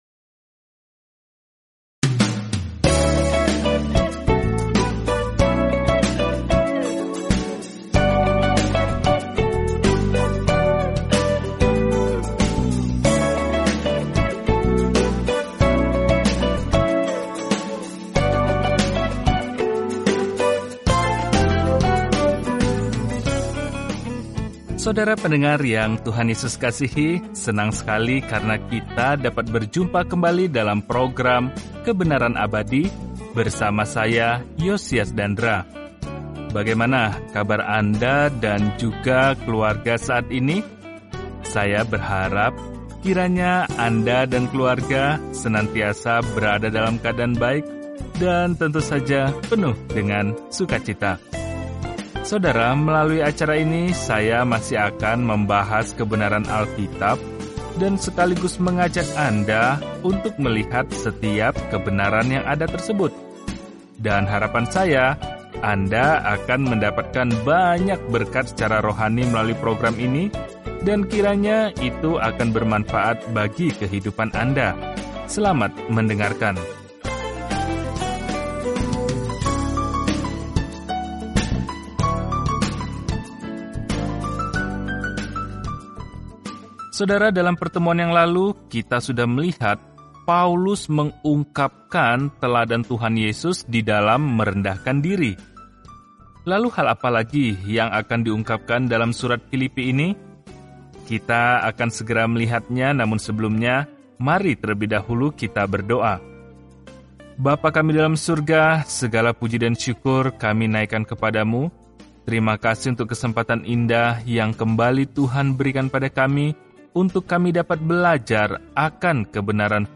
Firman Tuhan, Alkitab Filipi 2:9-11 Hari 7 Mulai Rencana ini Hari 9 Tentang Rencana ini Ucapan “terima kasih” kepada jemaat di Filipi ini memberi mereka perspektif yang menyenangkan tentang masa-masa sulit yang mereka alami dan mendorong mereka untuk dengan rendah hati melewatinya bersama-sama. Telusuri surat Filipi setiap hari sambil mendengarkan pelajaran audio dan membaca ayat-ayat tertentu dari firman Tuhan.